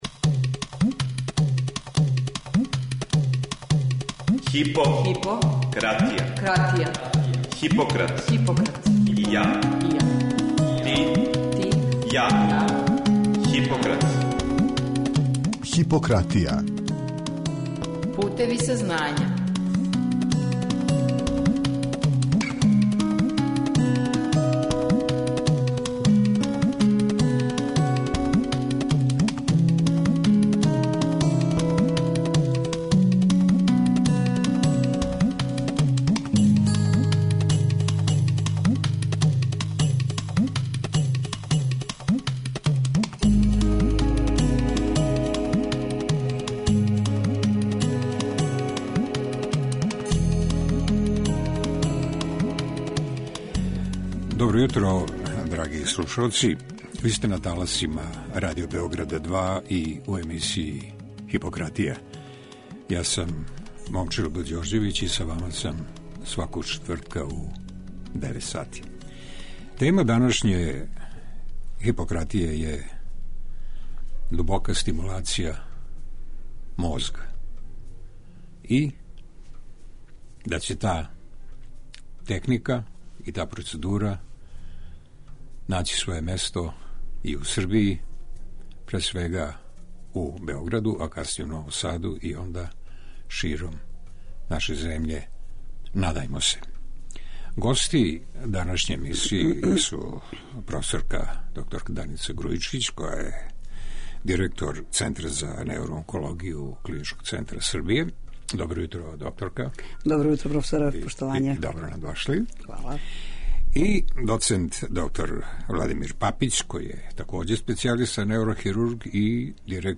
Гости емисије су професорка Даница Грујичић, специјалиста неурохирургије и директорка Центра за неуроонкологију КЦС